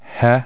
Es suave, como la h andaluza
Pronunciación